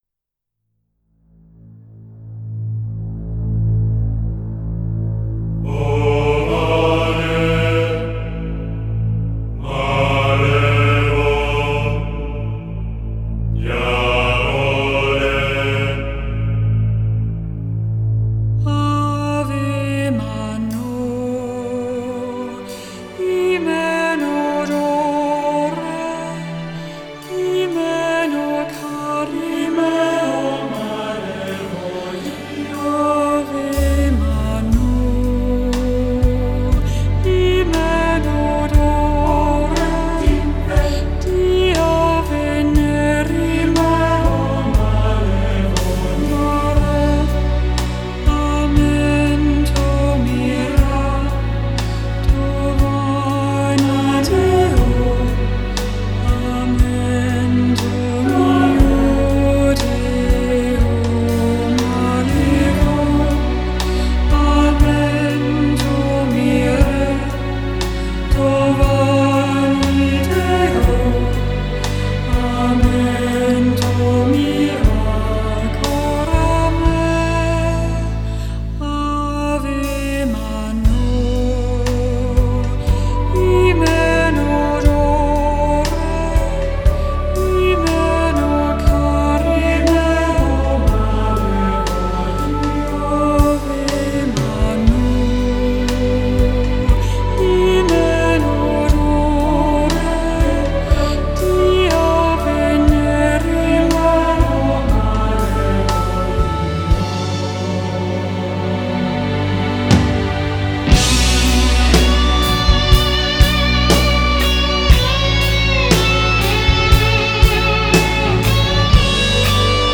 Genre : Ambient, Enigmatic, New Age